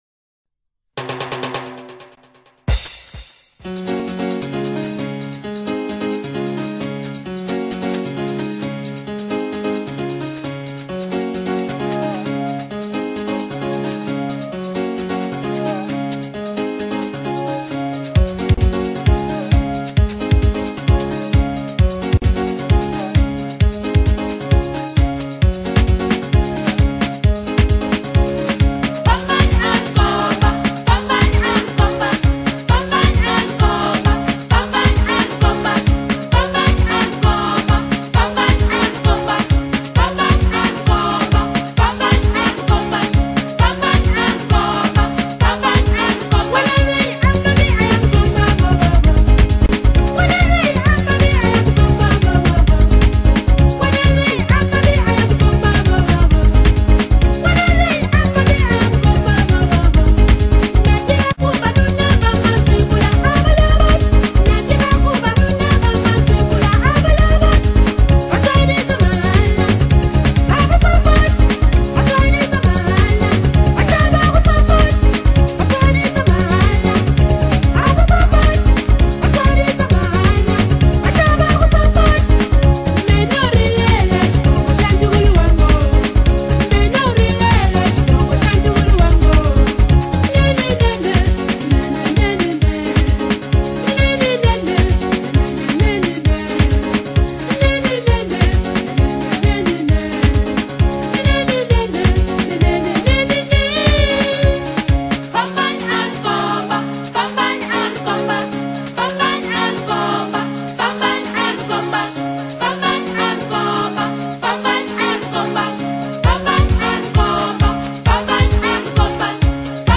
rhythmic innovation